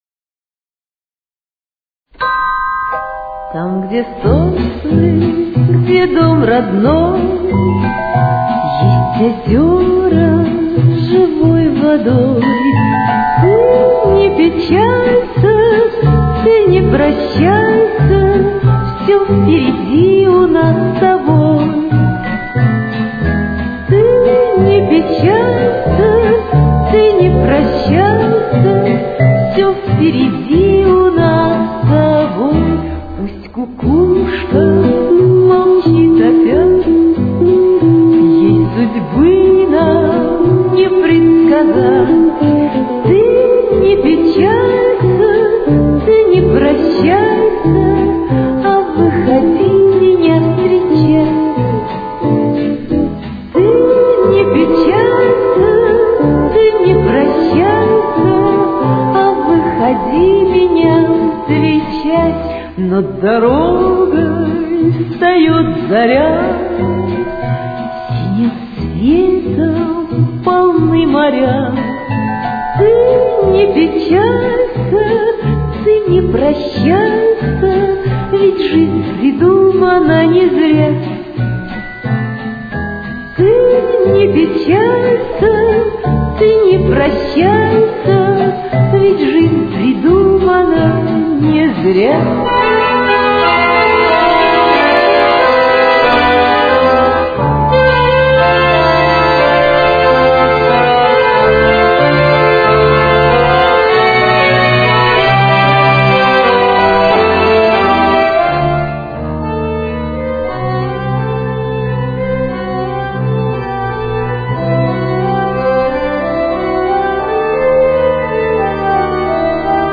с очень низким качеством (16 – 32 кБит/с)
Ми минор. Темп: 91.